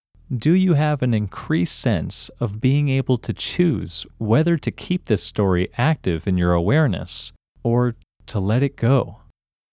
OUT – English Male 26